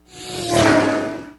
CarBy.wav